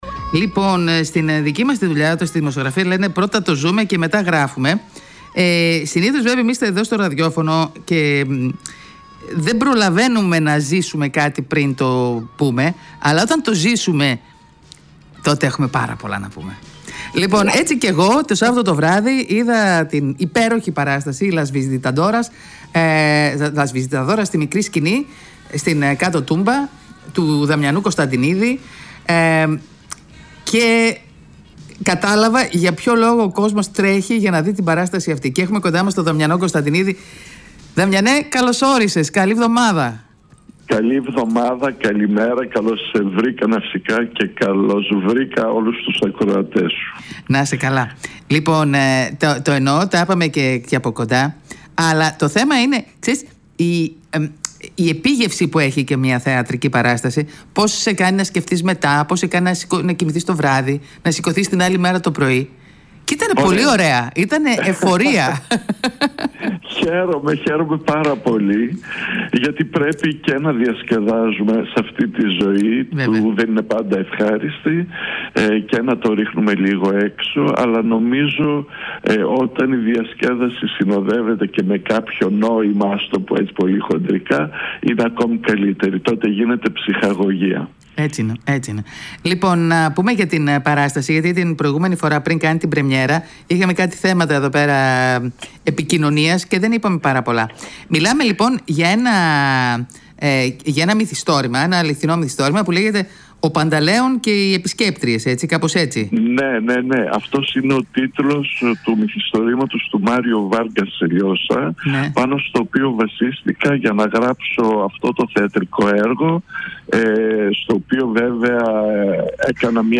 Las-Visitadoras-συνέντευξη-FM100.GR_.mp3